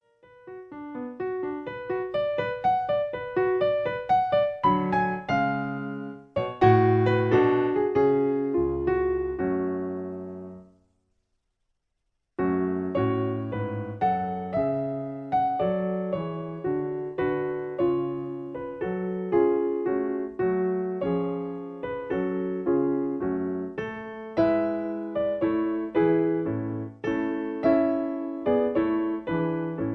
In F sharp. Piano Accompaniment